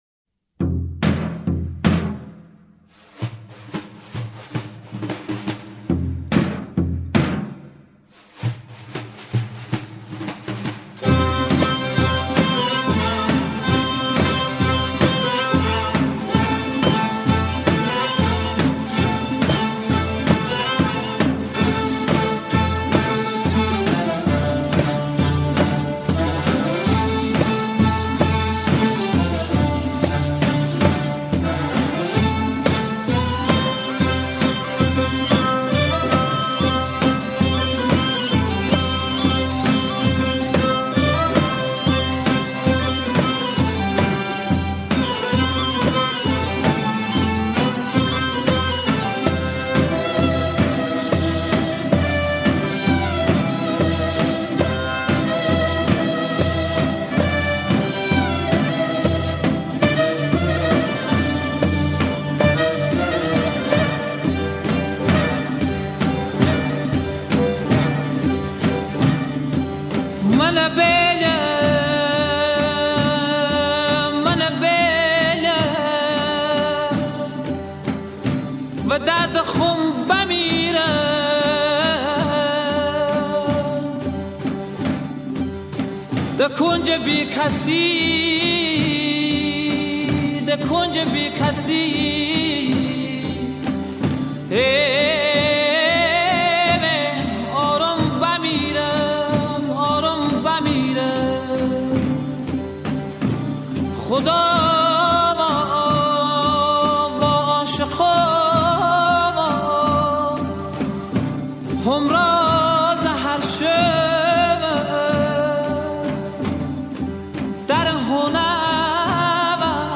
دانلود آهنگ لری لرستانی غمگین (خرم آبادی)
سبک: سنتی – گویش: مینجایی